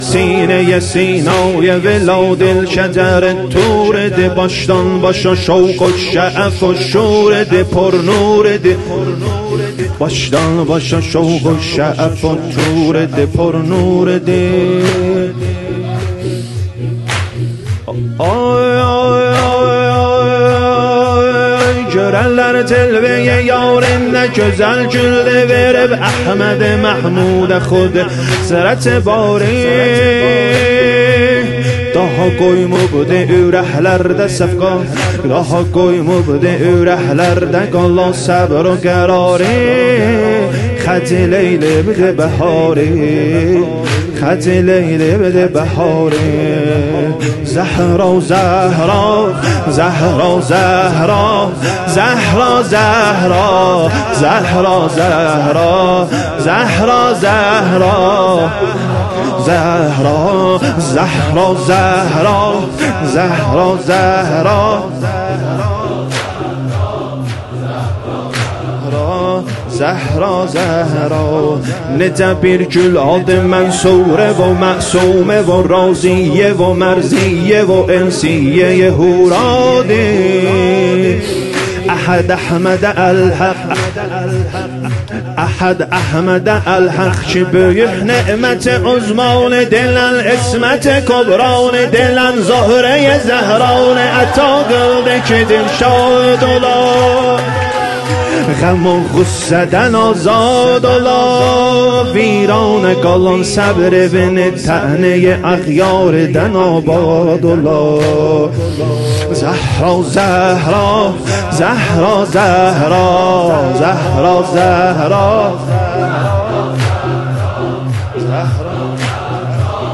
سینه زنی تک سینه سینای ولادی(ترکی
ایام فاطمیه اول - شب سوم